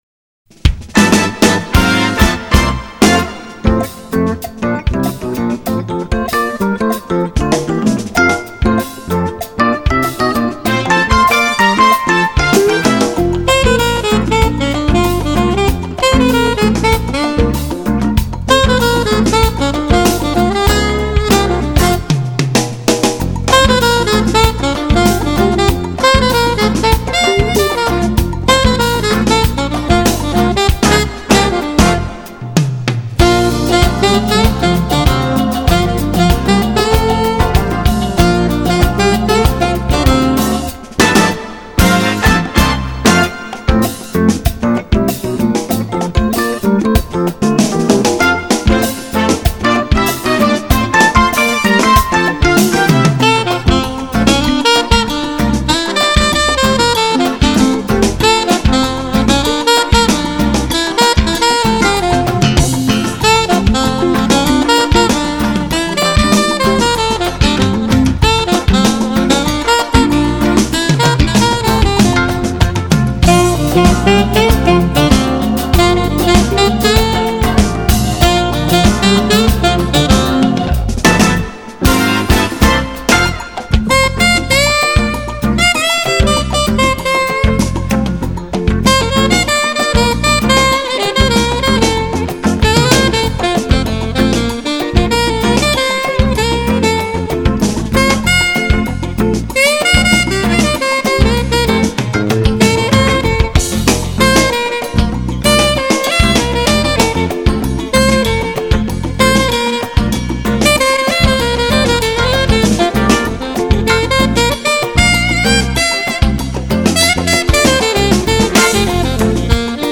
깔끔하게 뽑아내는 색소폰소리의 향연이 쥑이는군요